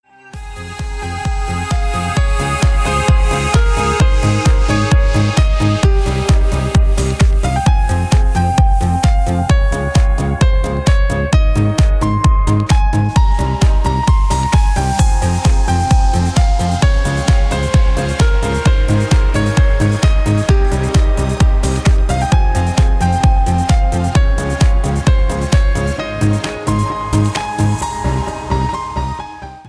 • Качество: 320, Stereo
громкие
мелодичные
без слов
progressive house